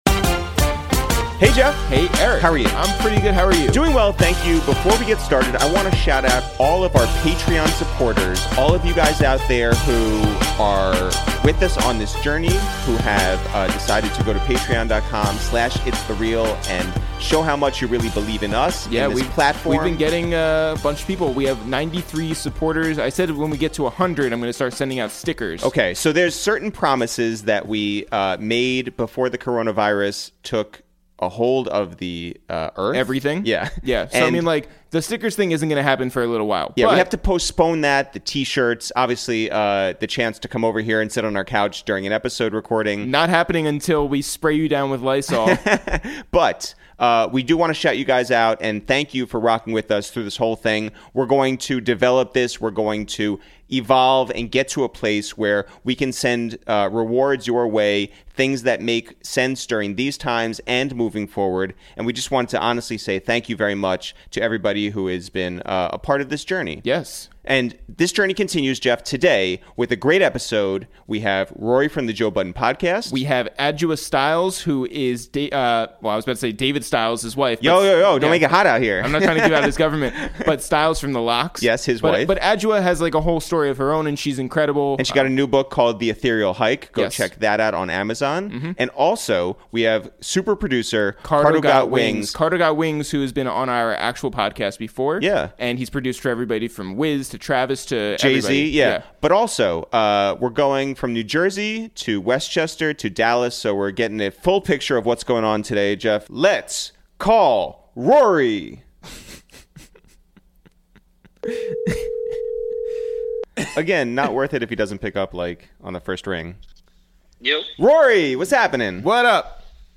we make calls from our Upper West Side apartment